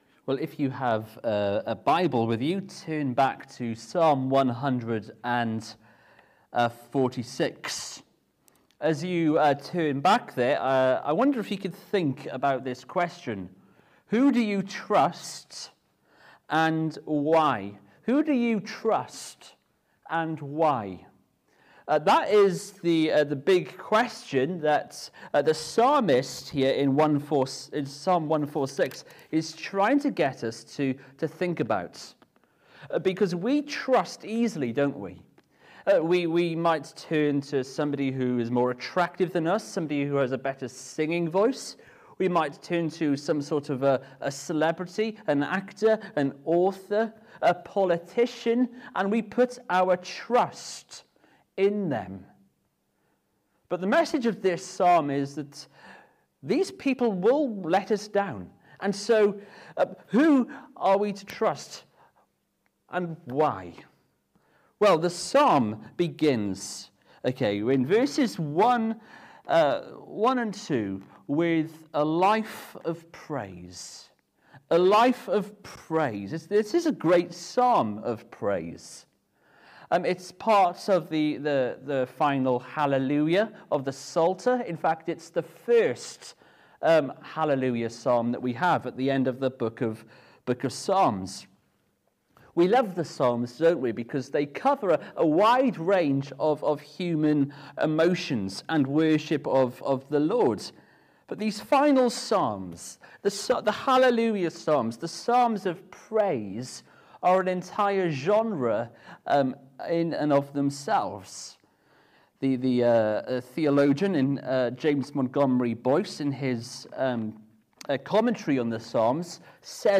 Psalm 146 Service Type: Evening Service We turn to Psalm 146 and ask the question